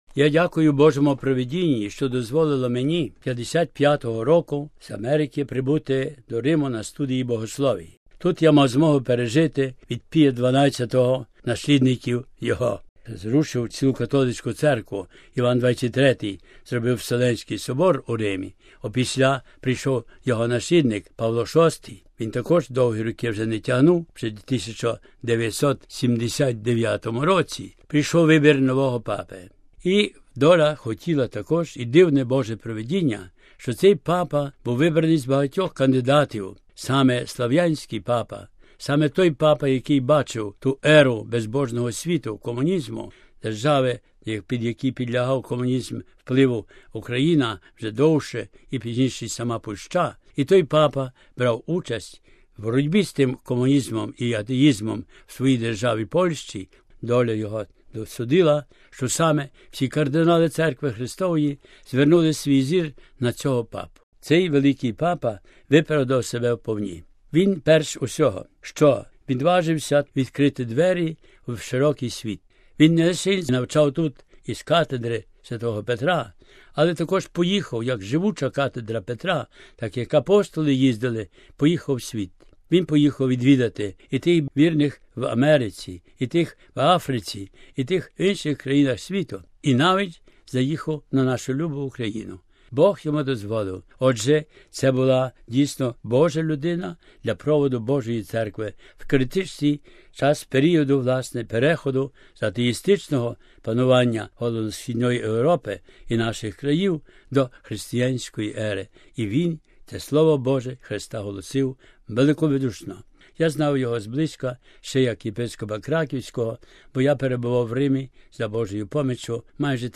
інтерв’ю з владикою Софроном Мудрим